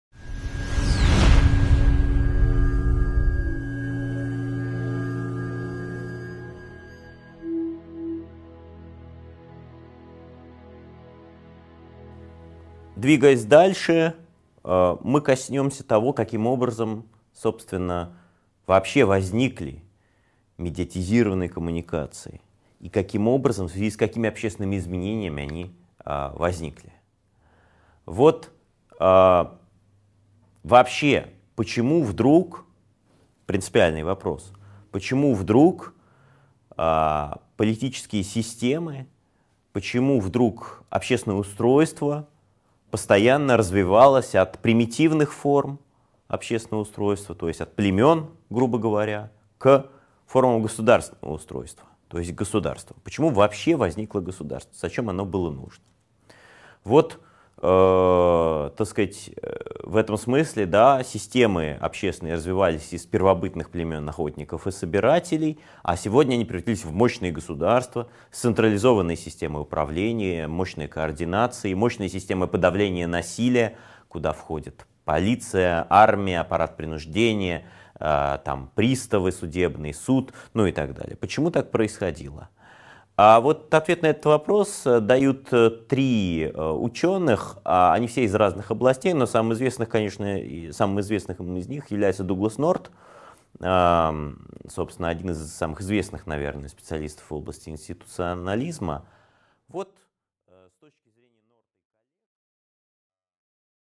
Аудиокнига 2.2 Медиа и общественные порядки | Библиотека аудиокниг